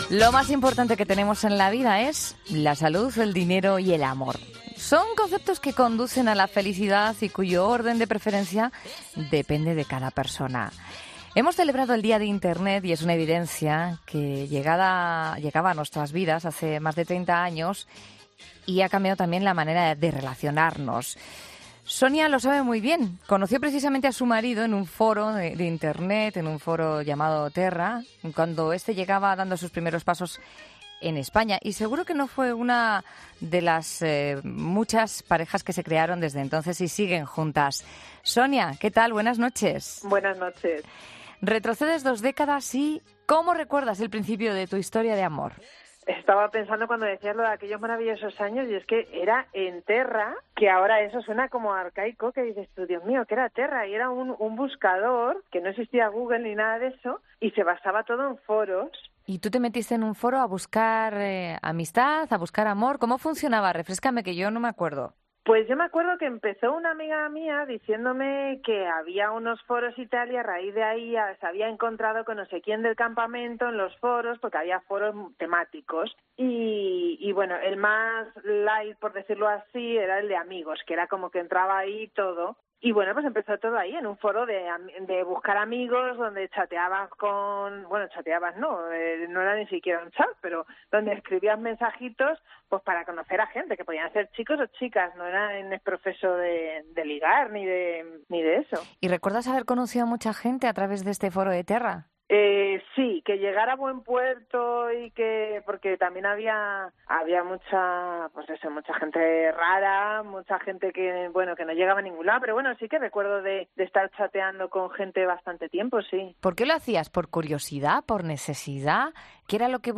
Hablamos con una mujer que conoció a su marido online en el año 2000